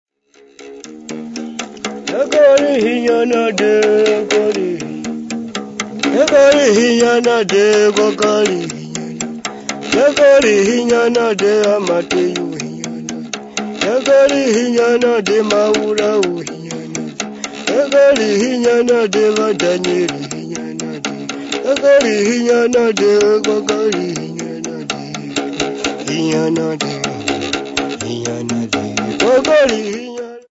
Folk music--Africa
Songs, Tumbuka
Field recordings
Africa Malawi Kasungu f-mw
The chief modification is the use of a straining bridge to increase the tension of the bark string.
The top segement was 3 ft. 10 ins. long and emitted a note of 71 vs. (81 vs. when stopped by clasping between the finger and thumb).
The interval between the two notes used is 220 cents. Self delectative song with Ugubu, musical bow, stressed and resonated